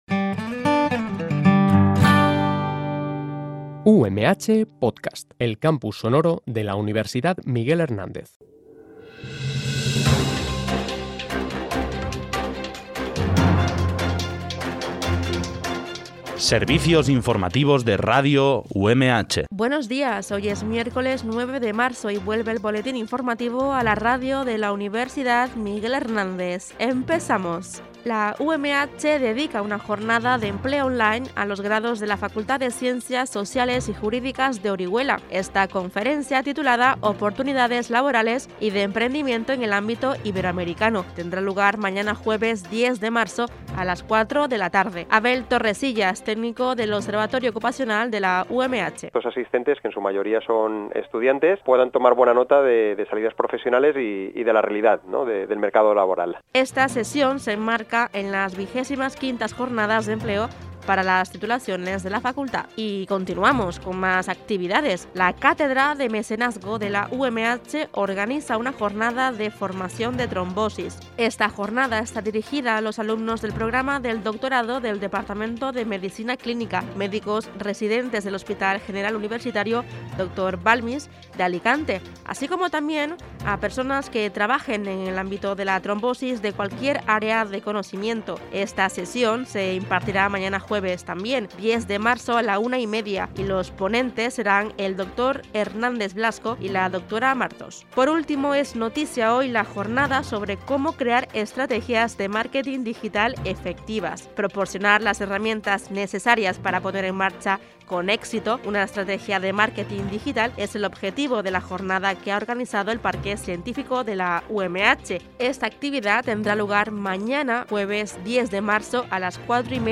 BOLETÍN INFORMATIVO UMH